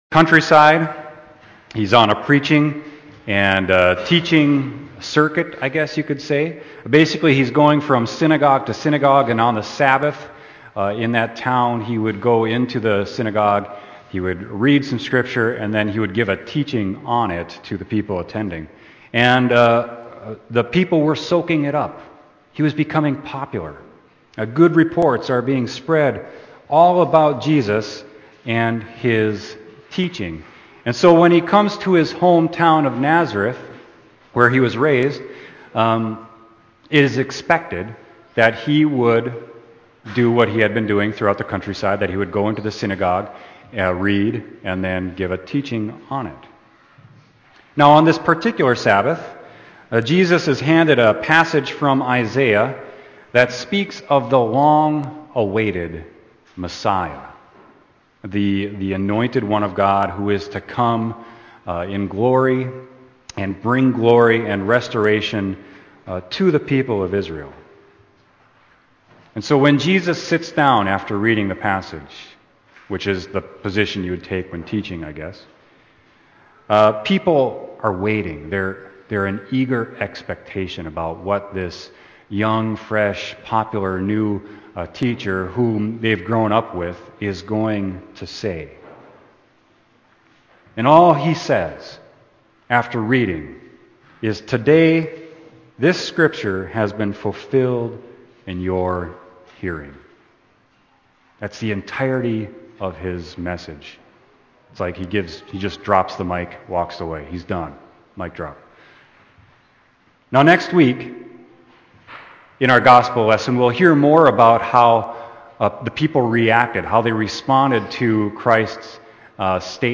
Sermon: Luke 4.14-21